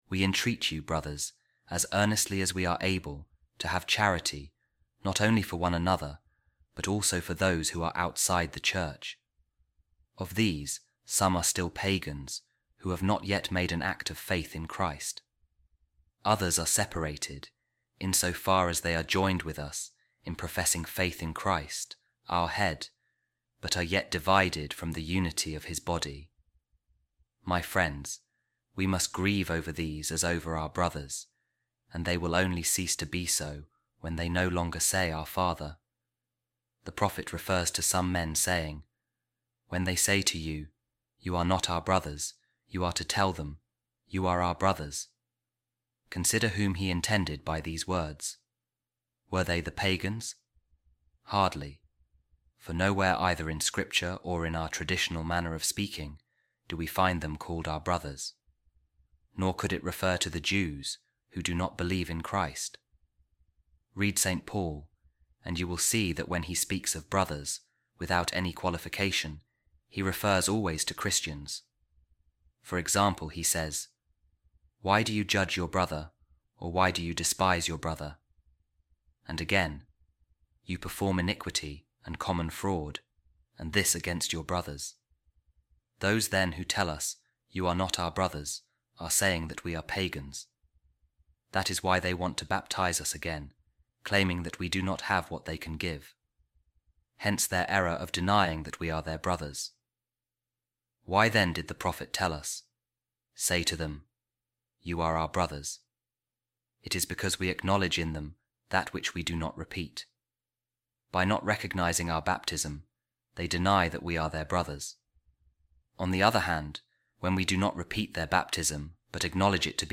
A Reading From The Discourses Of Saint Augustine On The Psalms | Psalm 32